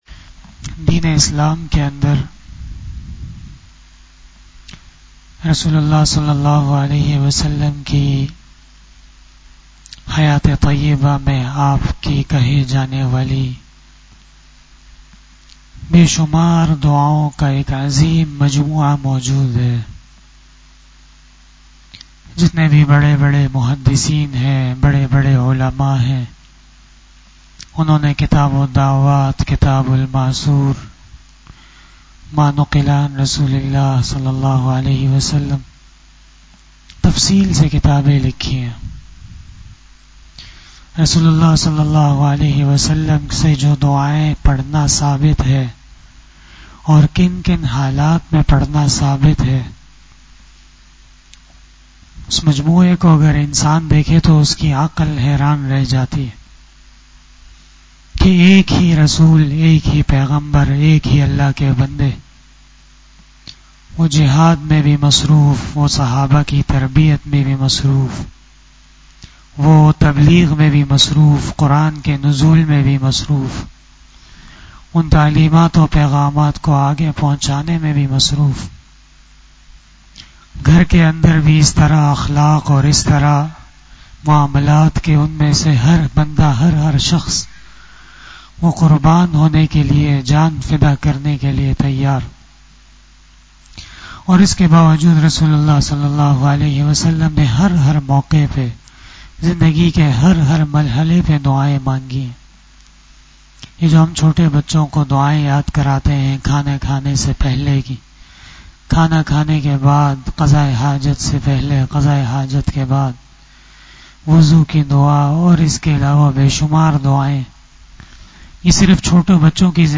After Fajar Namaz Bayan